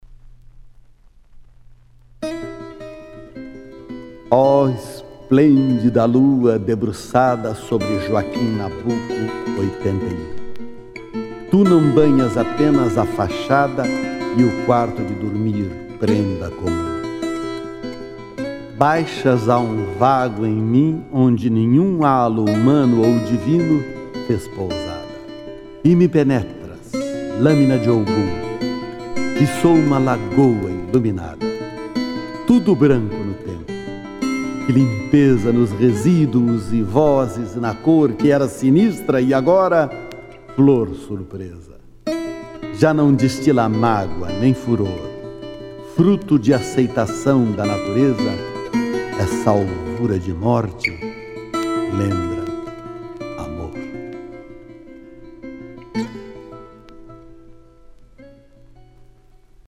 Carlos Drummond de Andrade interpretado por Lima Duarte - Músicas: Roberto Corrêa